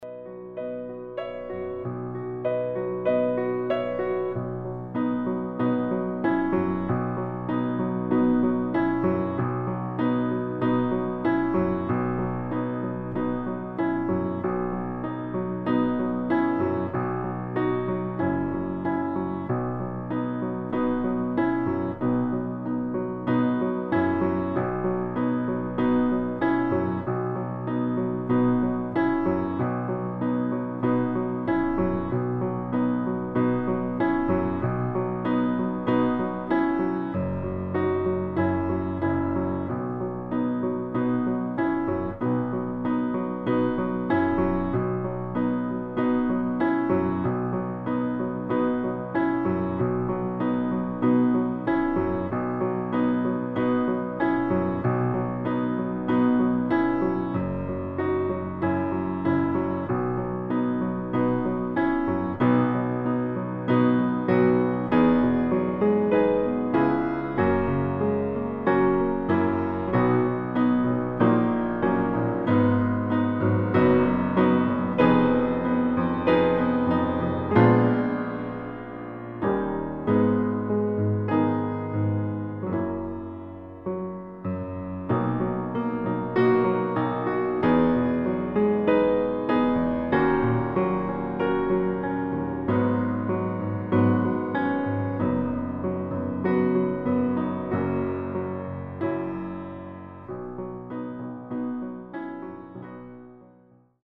• Tonart: H Dur
• Art: Flügel Einspielung
• Das Instrumental beinhaltet NICHT die Leadstimme
Klavier / Streicher